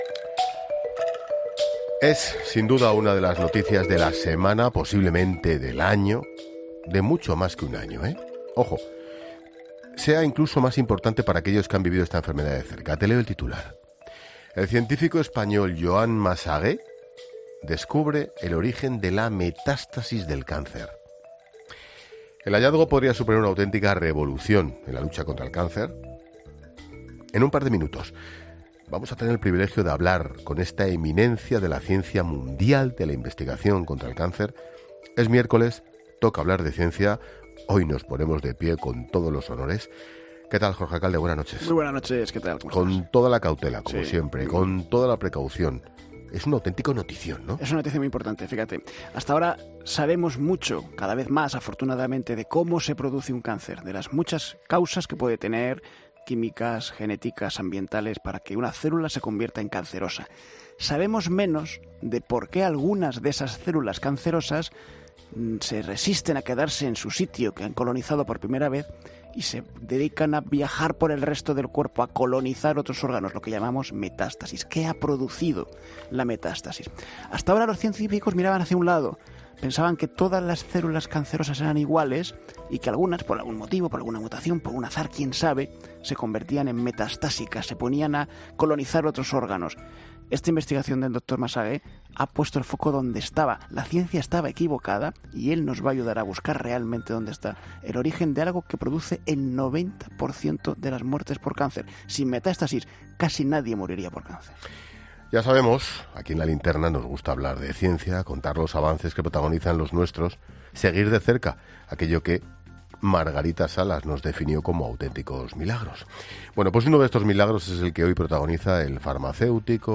El director de este Instituto norteamericano, que lleva décadas investigando la biología de la metástasis del cáncer, ha pasado por los micrófonos de ‘La Linterna’ este miércoles.